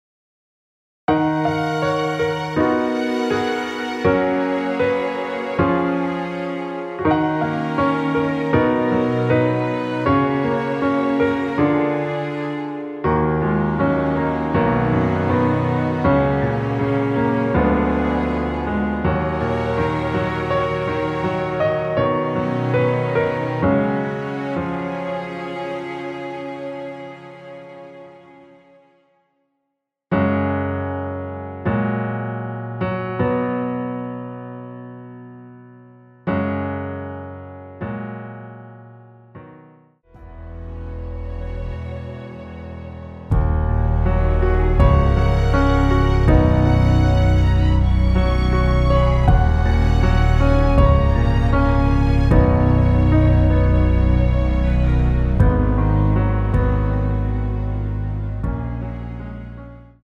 원키에서(-2)내린 MR입니다.
F#
앞부분30초, 뒷부분30초씩 편집해서 올려 드리고 있습니다.